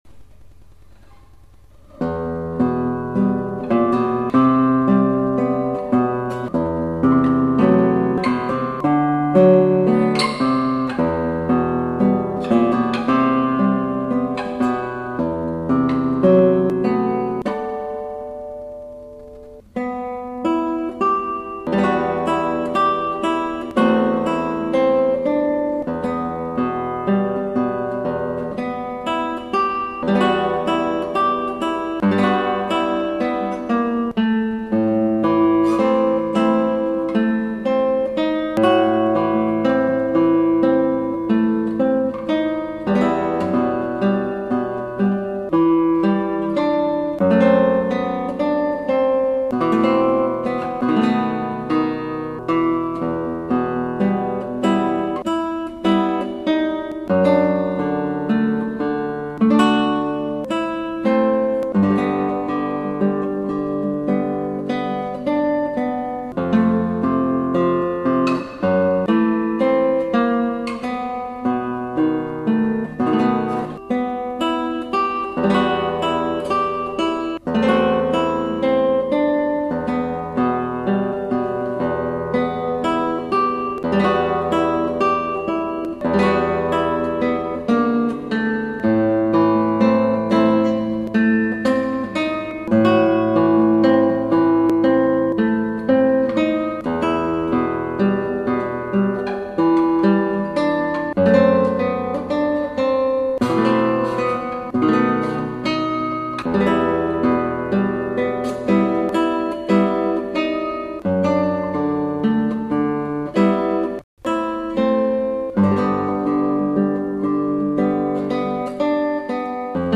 ヤイリギターで。